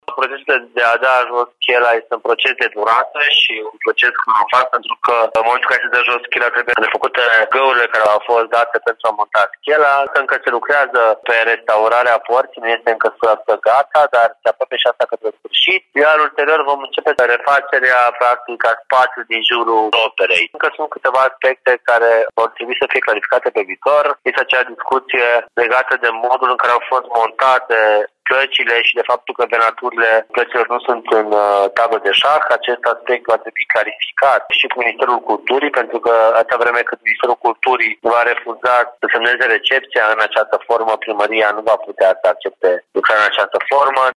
Șantierul va putea fi închis, cel mai probabil, luna viitoare, dar recepția lucrărilor mai are de așteptat, din cauza unor probleme, spune viceprimarul Ruben Lațcău.